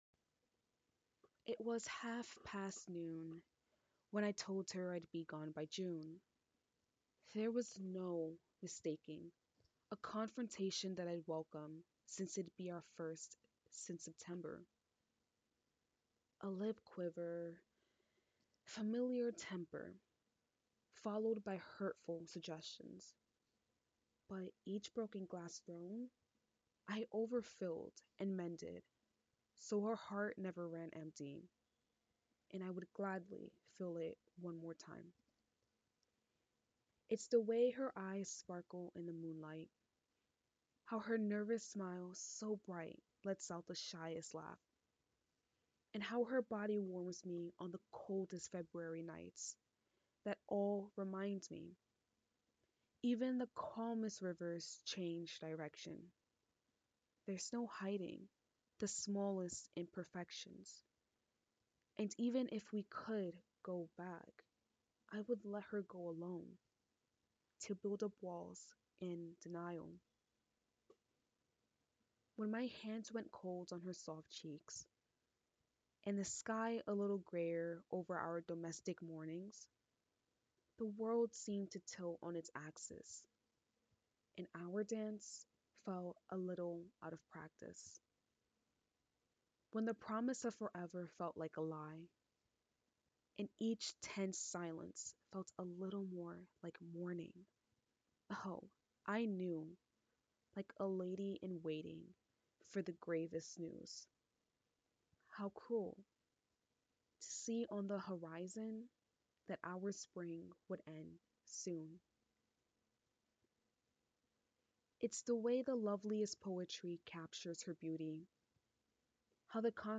spoken word (demo)